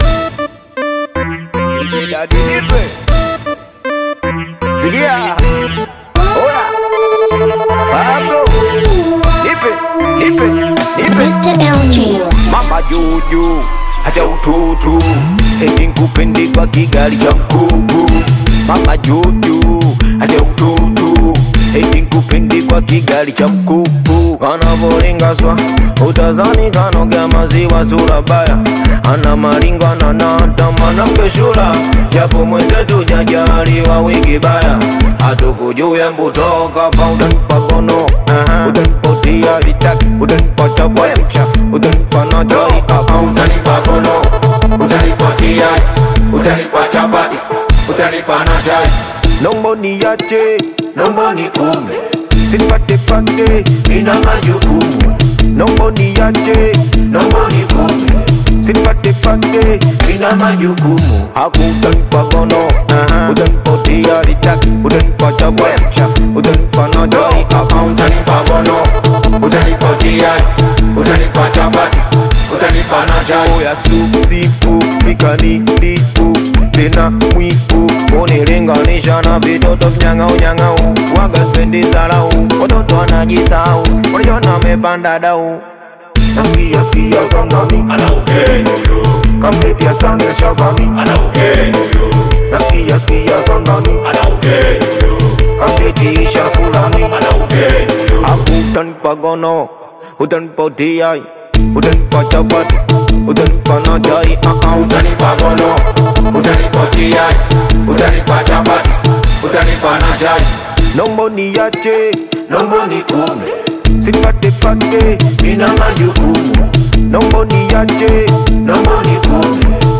is a fresh Amapiano/Afro-beat single released on February 6